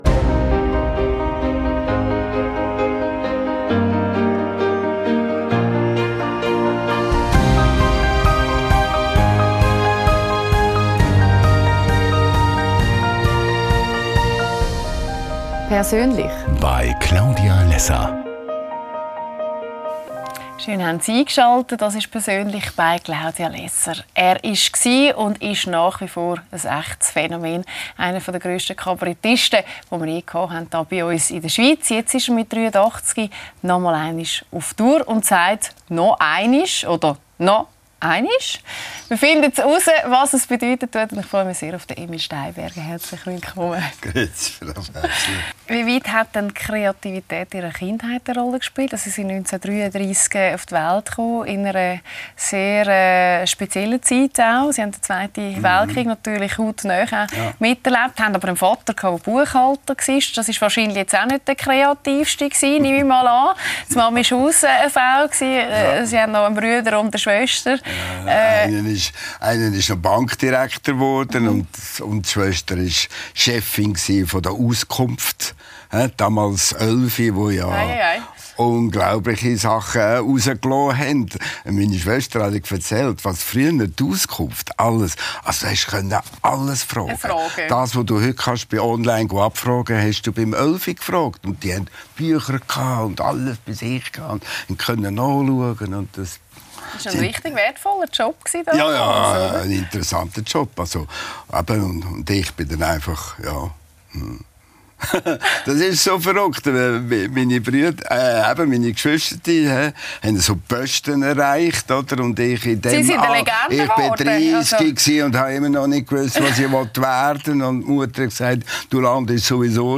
Lässer Classics – mit Emil Steinberger ~ LÄSSER ⎥ Die Talkshow Podcast